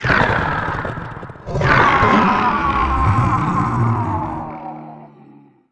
dead_1.wav